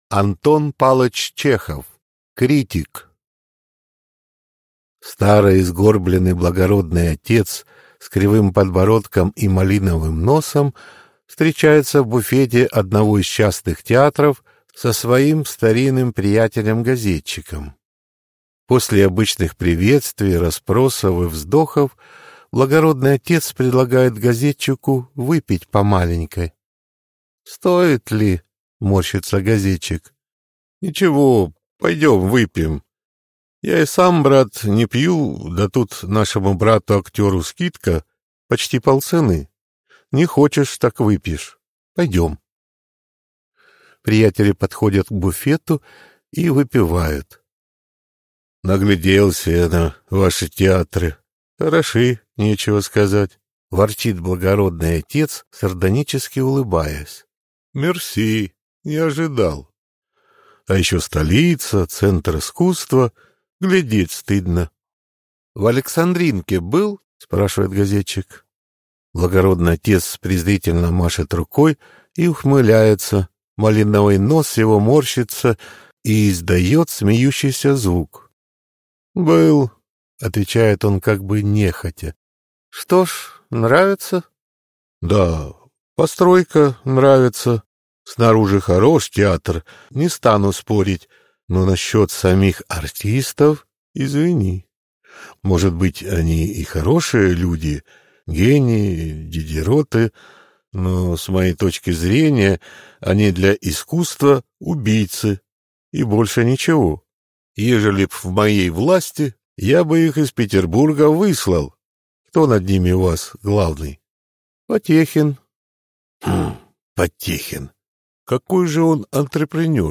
Аудиокнига Критик | Библиотека аудиокниг